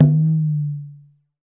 PRC TUMBA 0K.wav